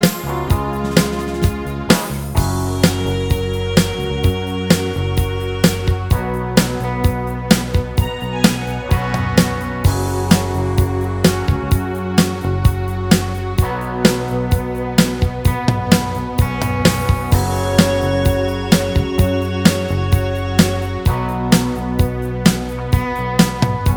Minus All Guitars Pop (1970s) 4:27 Buy £1.50